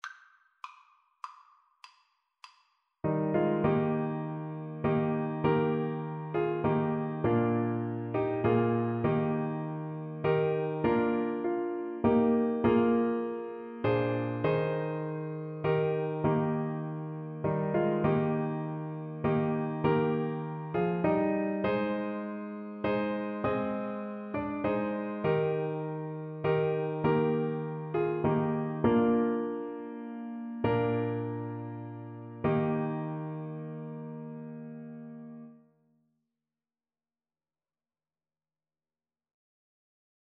Alto Saxophone
6/4 (View more 6/4 Music)
Eb5-Eb6
Classical (View more Classical Saxophone Music)